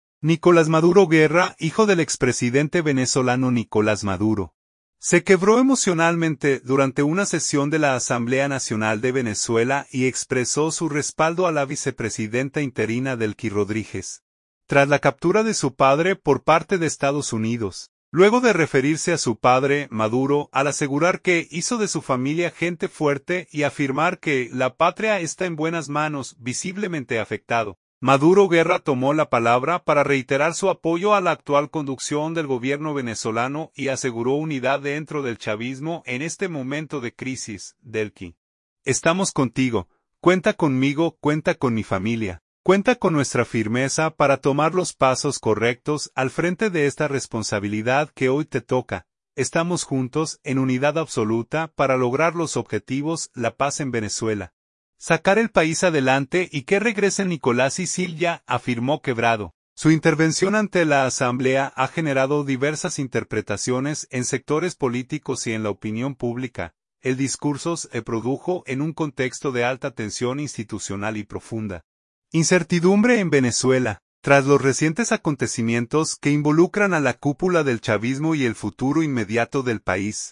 Nicolás Maduro Guerra, hijo del expresidente venezolano Nicolás Maduro, se quebró emocionalmente durante una sesión de la Asamblea Nacional de Venezuela y expresó su respaldo a la vicepresidenta interina Delcy Rodríguez, tras la captura de su padre por parte de Estados Unidos.
Luego de referirse a su padre, Maduro, al asegurar que “hizo de su familia gente fuerte”, y afirmar que “la patria esta en buenas manos”, visiblemente afectado, Maduro Guerra tomó la palabra para reiterar su apoyo a la actual conducción del Gobierno venezolano y aseguró unidad dentro del chavismo en este momento de crisis.